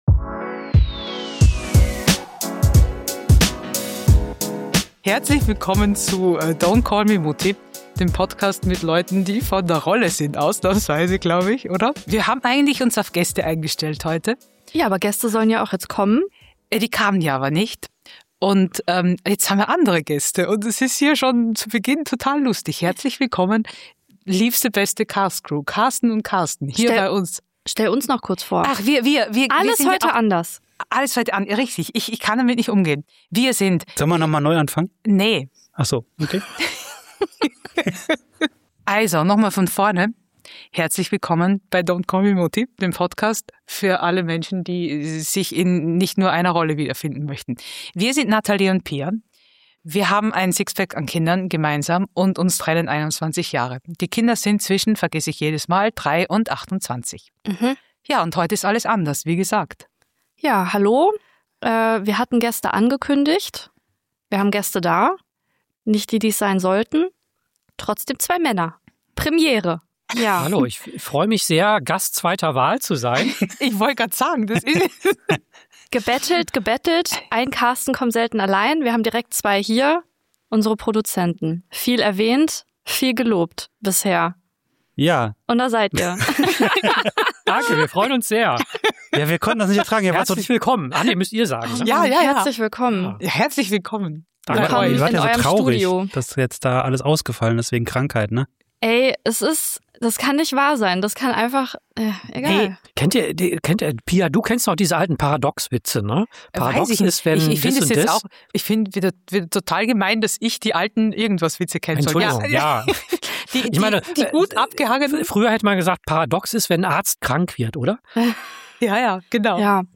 Und weil das Chaos ja irgendwer auffangen muss, wird aus der geplanten Folge kurzerhand eine spontane Runde mit Premiere: zwei Männer am Mikrofon und eine Stimmung zwischen „wir schaffen das“ und „ich bin geladen“. Es geht um das, was wir alle hassen: wenn Pläne scheitern.